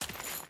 Footsteps / Dirt
Dirt Chain Walk 1.wav